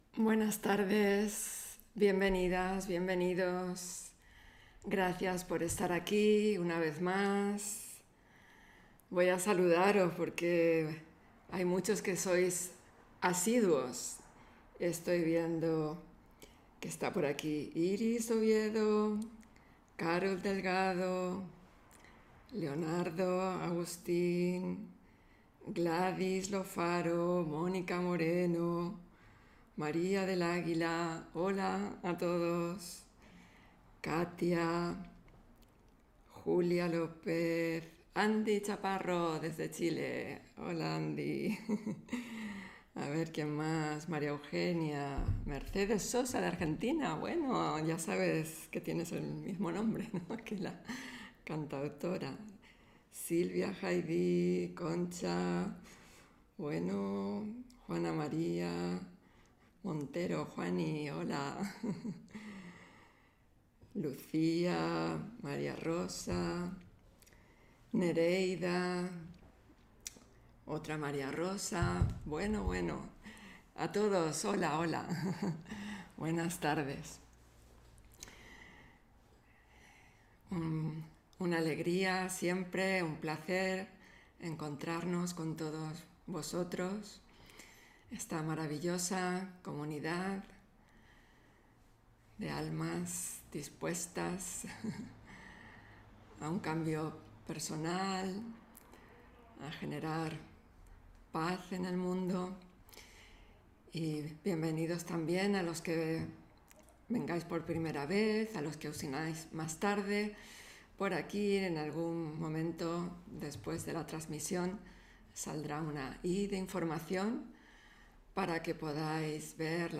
Meditación Raja Yoga y charla: Entregarse a la sabiduría de la incertidumbre (21 Diciembre 2020) On-line desde Madrid